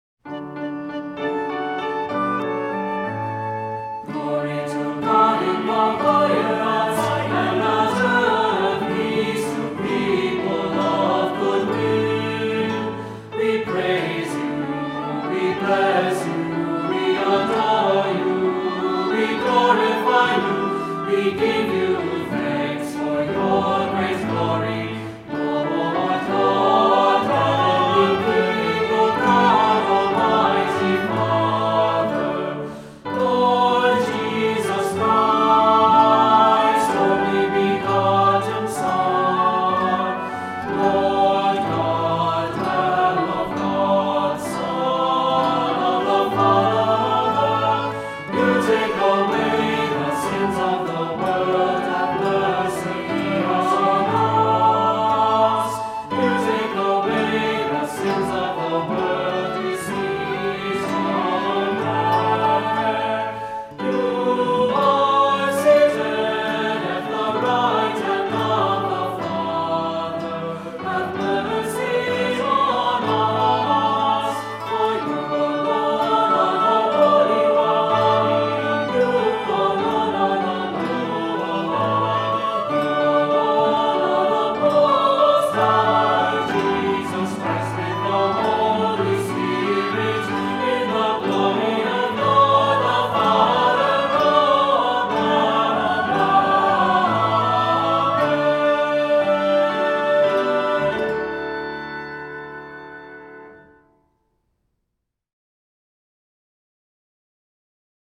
Voicing: Assembly, cantor,2-part Choir